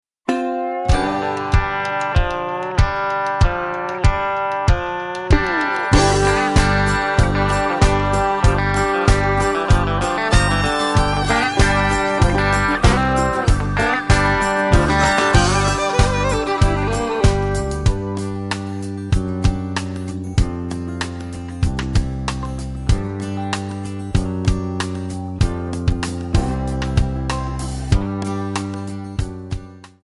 F#
MPEG 1 Layer 3 (Stereo)
Backing track Karaoke
Country, 2000s